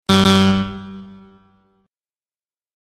エラーやキャンセルの瞬間を強烈に印象づけるサウンドです。
エラー音（ゲーム・ミス・不正解・間違い・失敗・クイズ・イベント ） 05 着信音